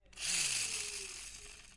玩具车拉回轮子
描述：玩具车被拉回来并举起
Tag: 车轮 正在压缩 嗖嗖 汽车 公平 OWI 玩具